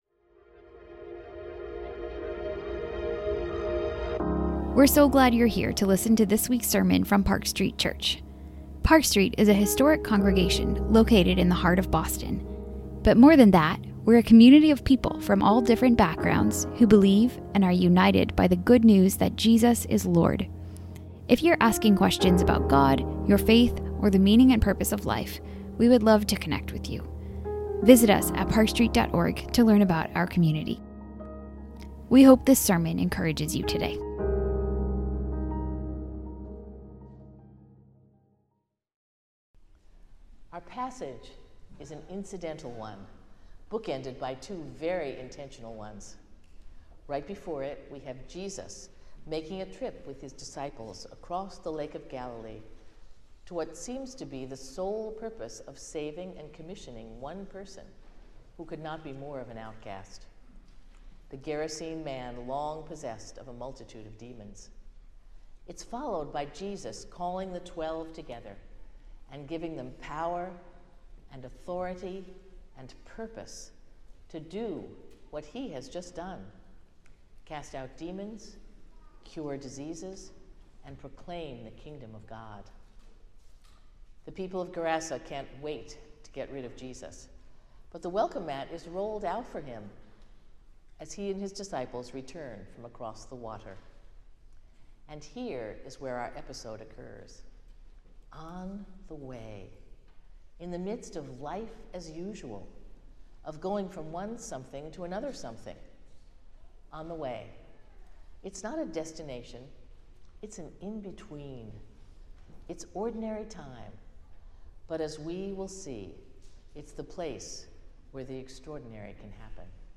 This sermon explores how Revelation calls us to urgent, faithful, worshipful, Christ-centered, and hope-filled witness amidst conflict as we wait for Christ to return.